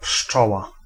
Ääntäminen
US : IPA : [bi]